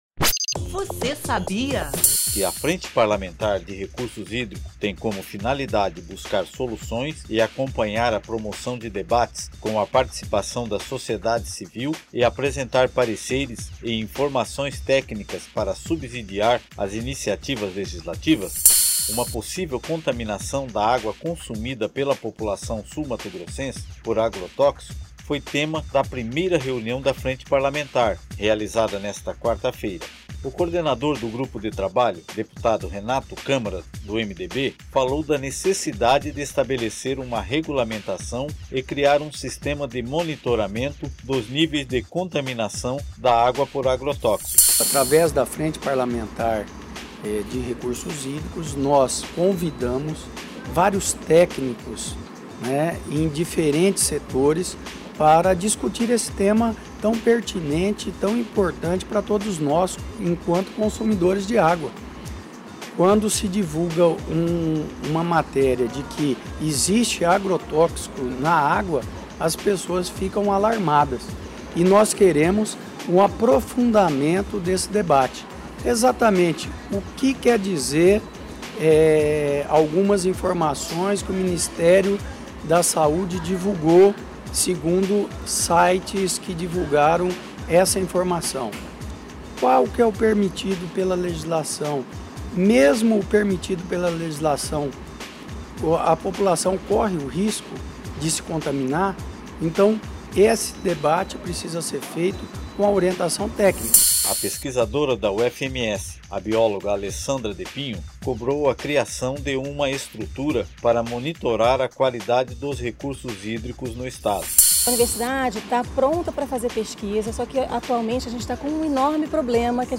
Convidadas para esclarecer os questionamentos relativos à qualidade da água distribuída aos consumidores sul-mato-grossenses, as concessionárias Sanesul e Águas Guariroba afirmaram que o produto fornecido encontra-se dentro dos parâmetros estabelecidos pela legislação brasileira. Os dados foram informados durante a reunião da Frente Parlamentar de Recursos Hídricos de Mato Grosso do Sul, proposta e coordenada pelo deputado Renato Câmara (MDB).